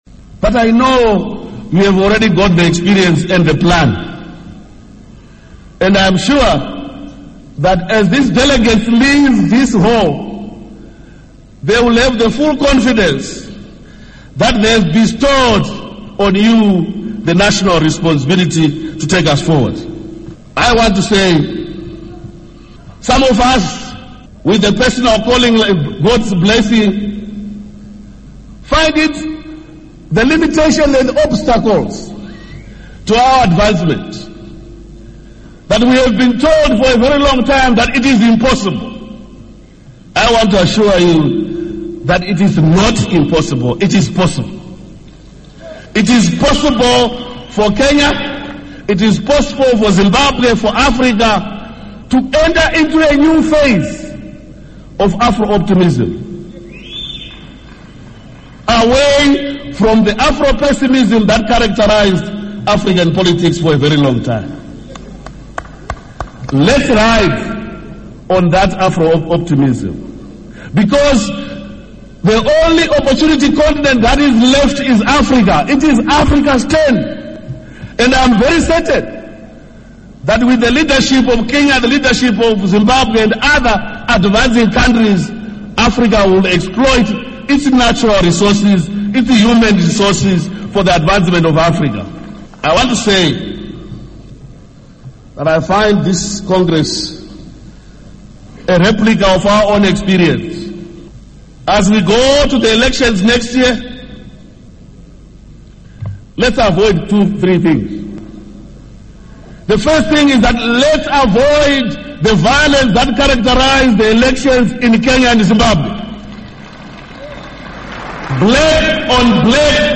UMnu. Tsvangirai ubekhuluma eseNairobi eKenya emhlanganweni weOrange Democratic Movement, elikhokhelwa nguMnu. Raila Odinga